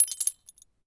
GLASS_Fragment_14_mono.wav